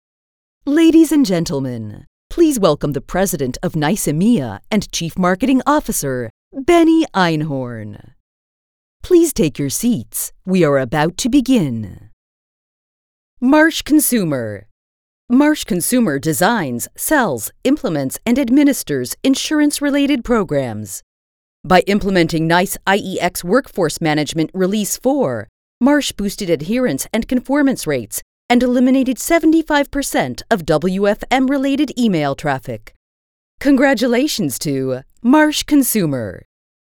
Spécialisée dans l'e-learning et la narration technique, elle offre un doublage professionnel, chaleureux, autoritaire et rapide, idéal pour les projets d'entreprise, éducatifs et axés sur les personnages.
Annonces
* Studio traité acoustiquement, pour garantir un son propre et de haute qualité